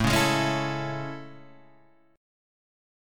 A Major 7th
AM7 chord {5 4 6 6 5 4} chord